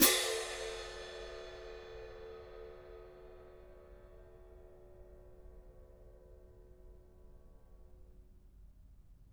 cymbal-crash1_pp_rr1.wav